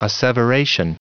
Prononciation du mot asseveration en anglais (fichier audio)
Prononciation du mot : asseveration